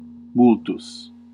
Ääntäminen
Ääntäminen Classical: IPA: /ˈmʊl.tʊs/ Haettu sana löytyi näillä lähdekielillä: latina Käännöksiä ei löytynyt valitulle kohdekielelle.